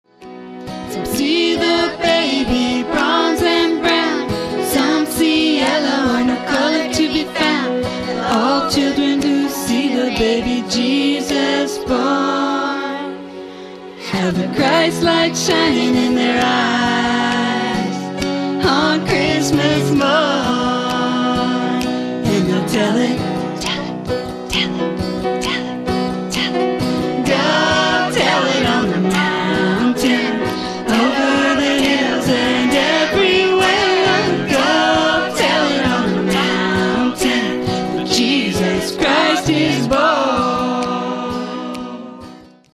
Traditional Tunes Sung With Transformational Thoughts